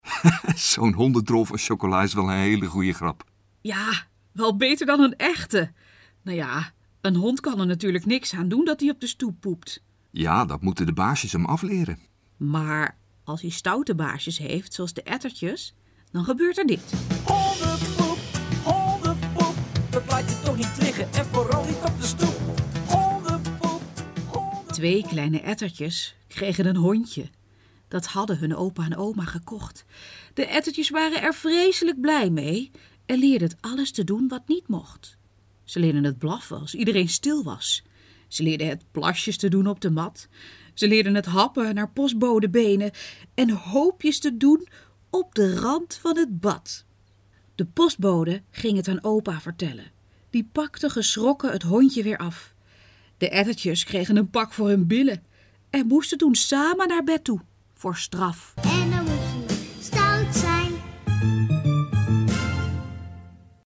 Hardop is hét luistertijdschrift voor blinde en slechtziende kinderen tussen de 5 en 10 jaar en staat iedere vier weken weer vol verhalen en gedichten passend binnen een steeds weer nieuw thema.